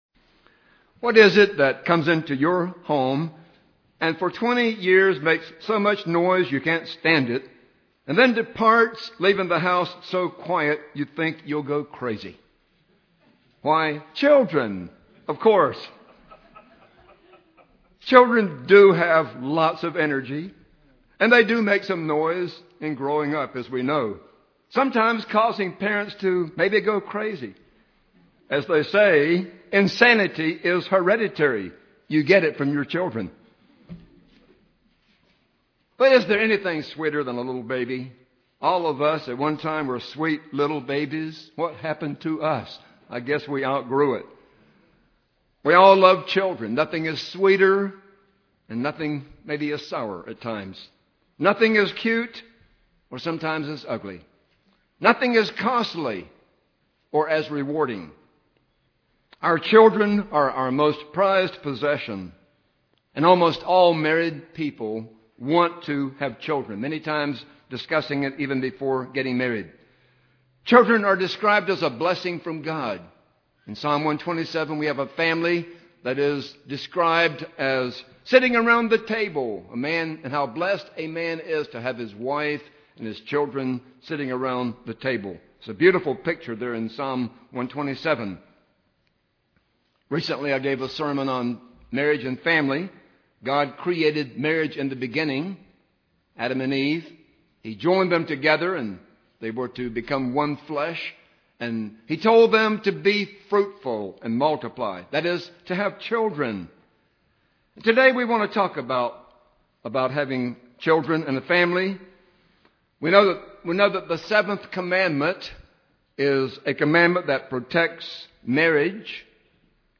Listen to this sermon to learn how keeping the Fifth Commandment helps us to prepare for life in an Eternal Family.